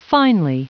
Prononciation du mot finely en anglais (fichier audio)
Prononciation du mot : finely